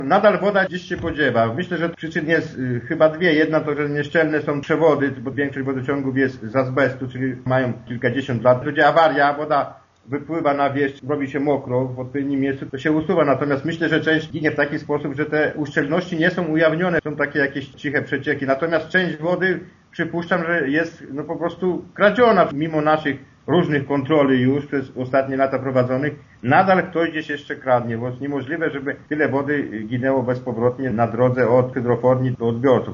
„Wprawdzie po dotychczasowych kontrolach sytuacja nieco się poprawiła, ale różnica nadal jest duża” – mówi wójt Gminy Gościeradów Jan Filipczak: